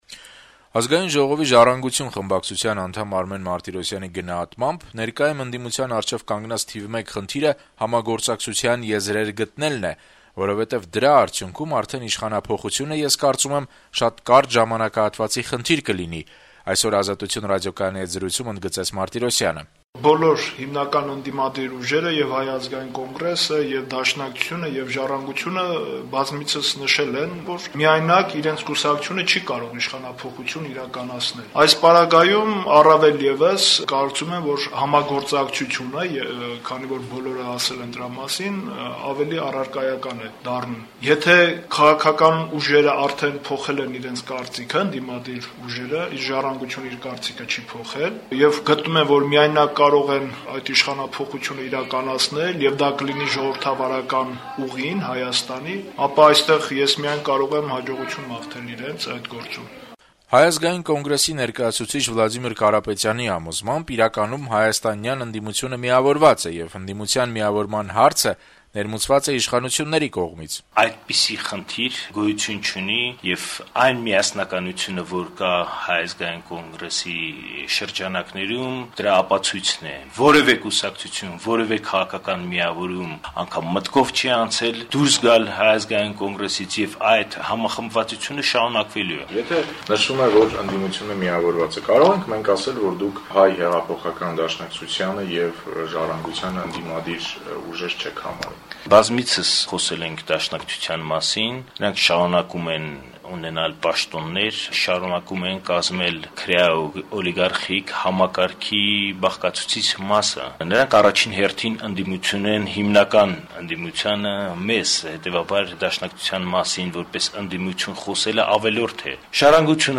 Ընդդիմադիր ուժերի ներկայացուցիչները «Ազատություն» ռադիոկայանի հետ զրույցներում իրենց կարծիքներն են հայտնում միավորման հեռանկարների վերաբերյալ: